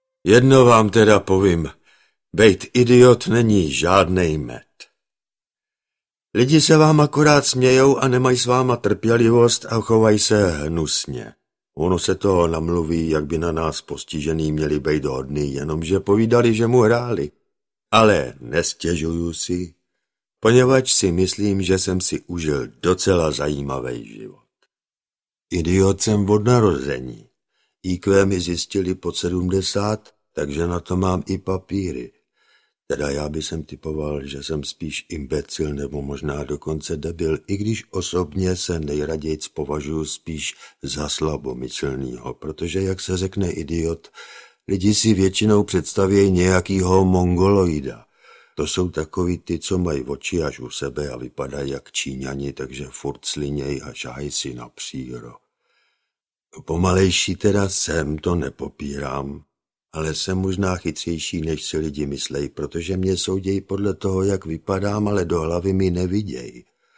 Audiobook
Read: Jan Hartl